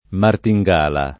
martingala [ marti jg# la ] s. f.